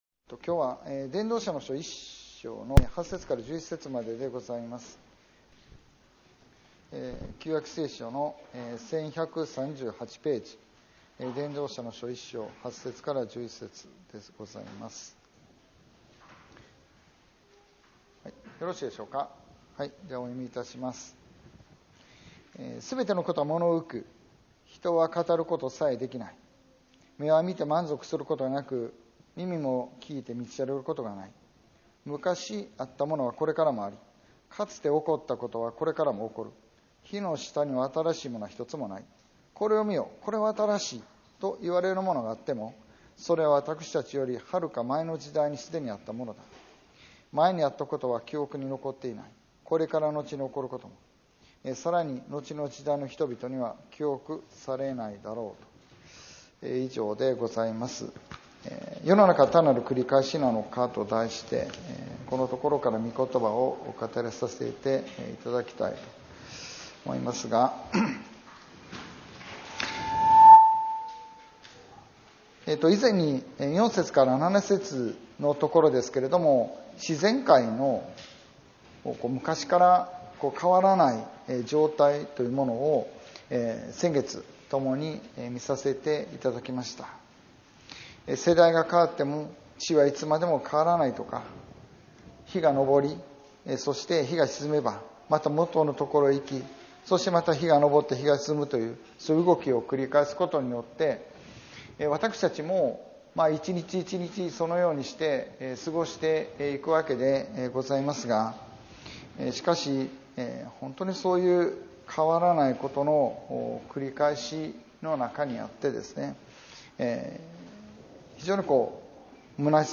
2025年7月27日礼拝説教「世の中は単なる繰り返しなのか」